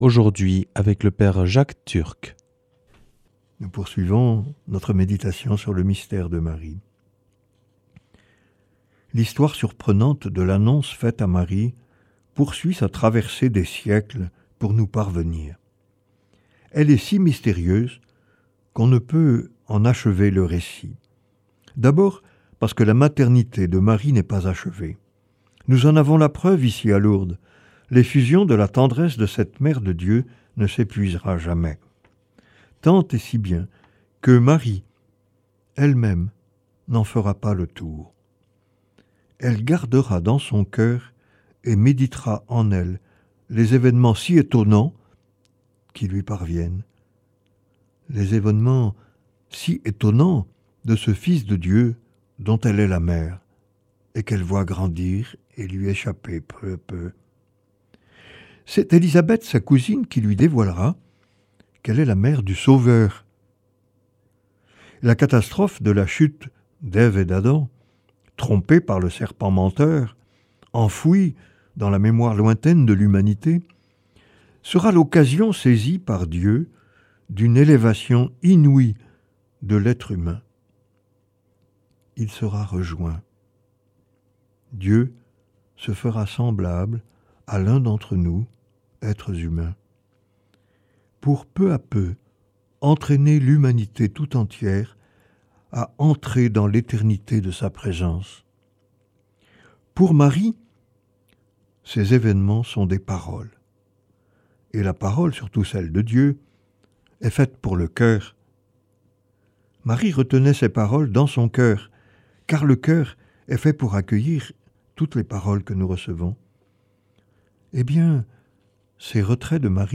jeudi 6 mars 2025 Enseignement Marial Durée 10 min